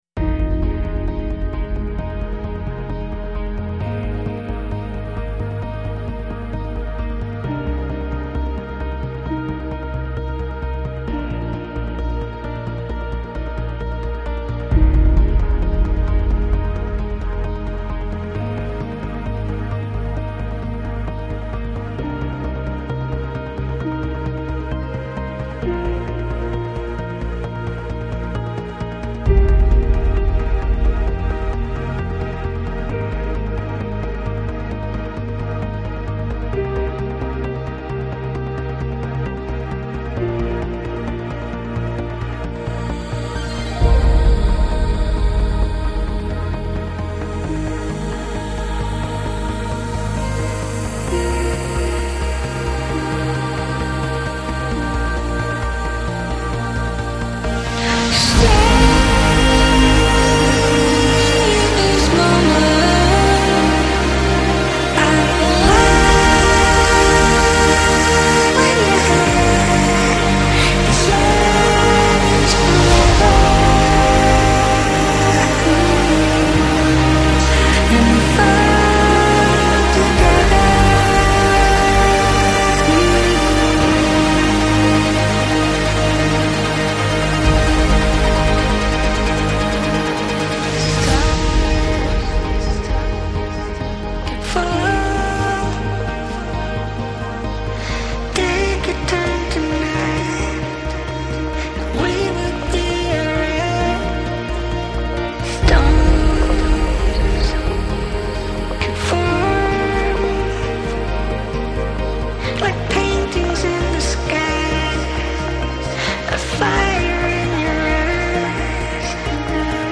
Жанр: Modern Classical